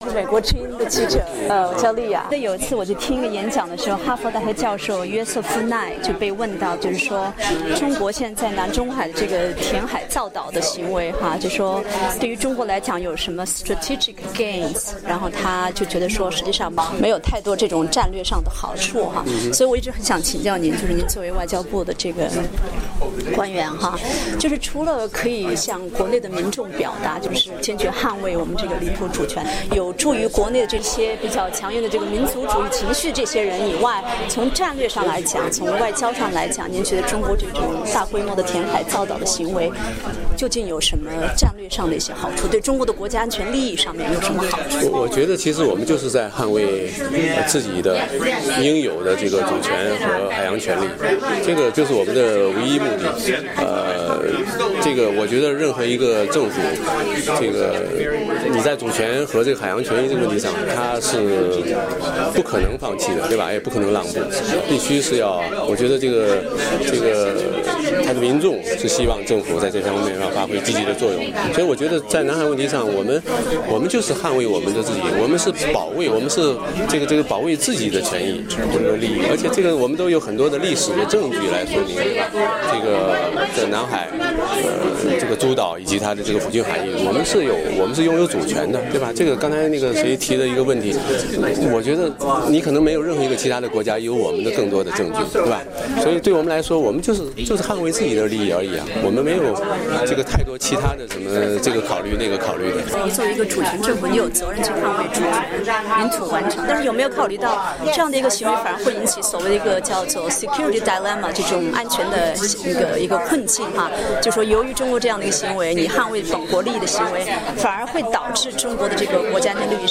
中国驻美大使馆公使衔参赞、前外交部发言人刘为民日前在华盛顿智库参加一个有关南中国海问题的讨论后罕见的接受了美国之音记者的专访，对这种质疑做出回应。